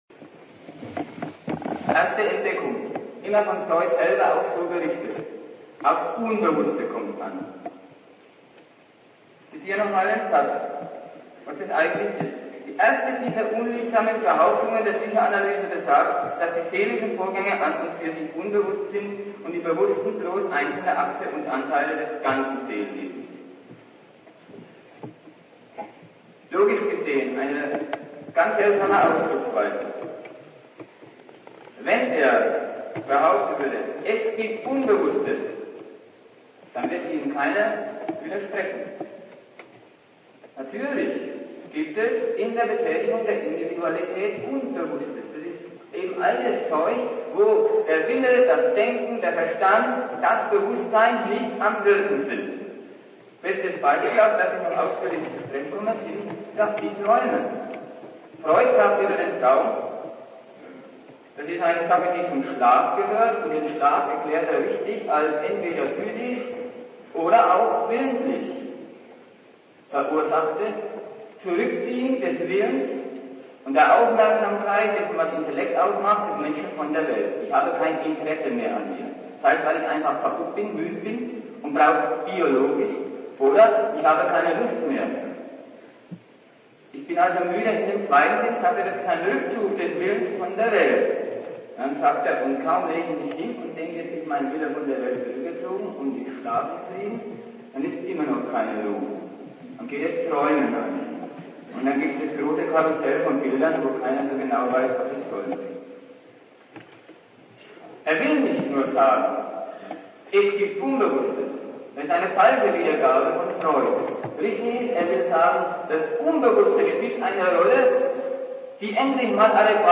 München
Es handelt sich um eine alte Aufnahme, die im Jahr 1978 aufgenommen wurde. Die akustische Qualität ist z.T. grenzwertig. Zum Abhören sollten Kopfhörer benutzt werden.